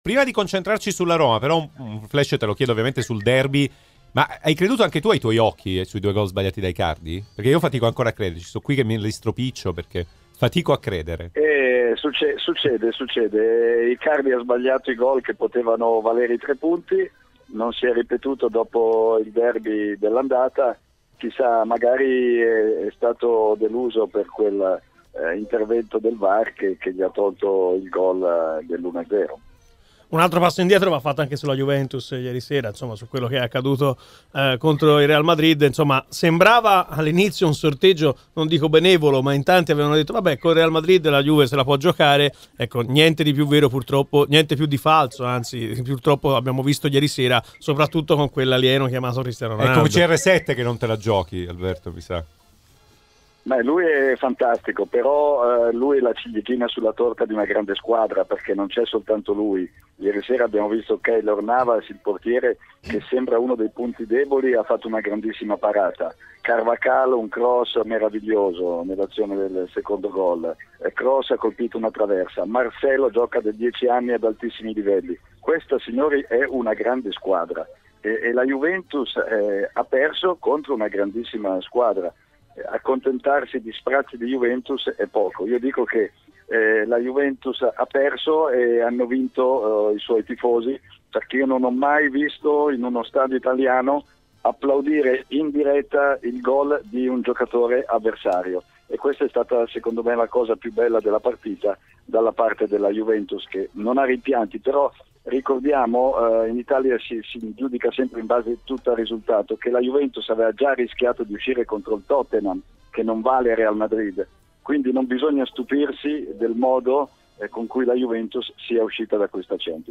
© registrazione di TMW Radio